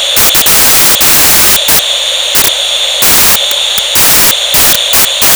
Stationary only
hiss
misty_idle_stat_steam.wav